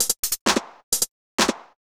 130_HH+clap_1.wav